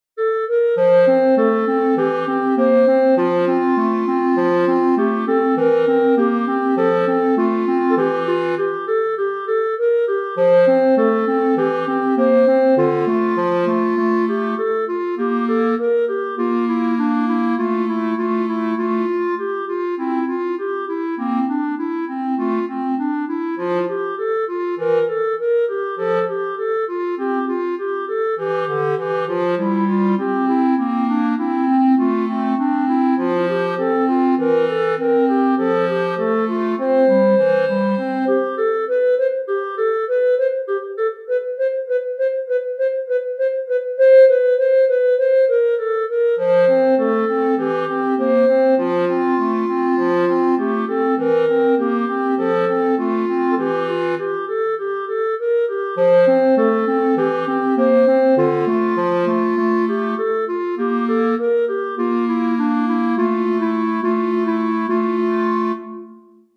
Répertoire pour Clarinette - 2 Clarinettes